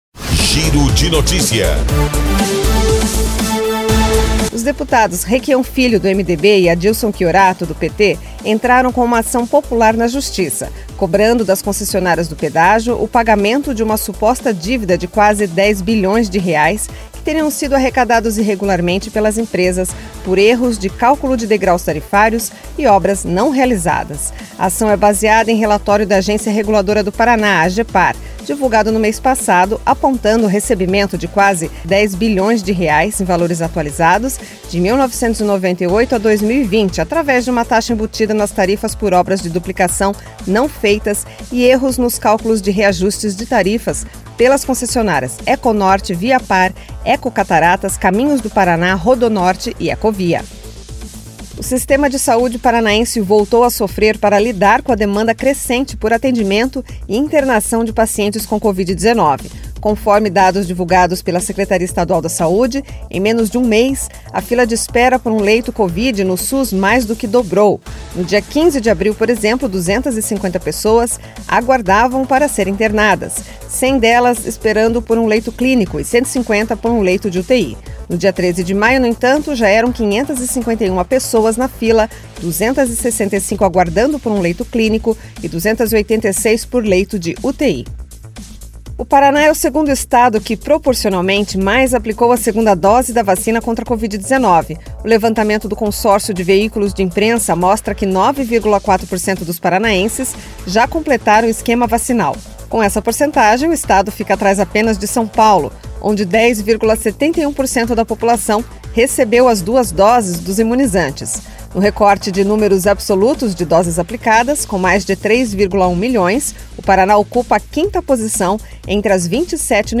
Giro de Notícias Manhã COM TRILHA